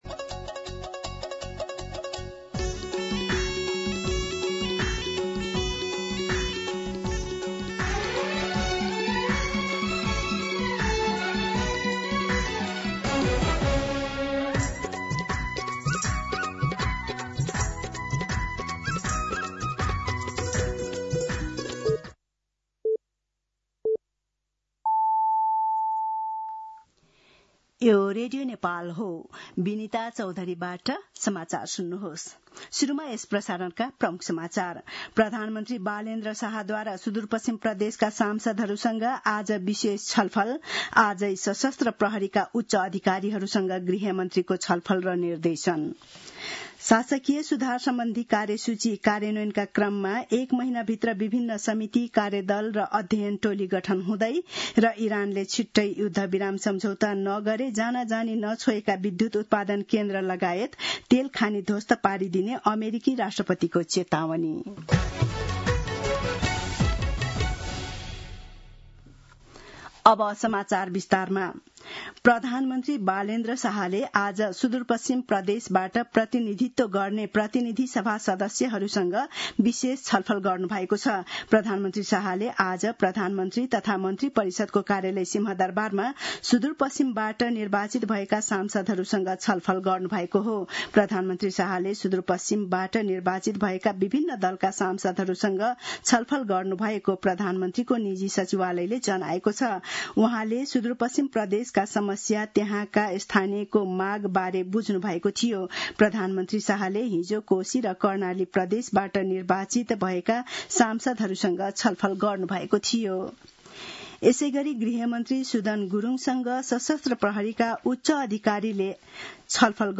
दिउँसो ३ बजेको नेपाली समाचार : १७ चैत , २०८२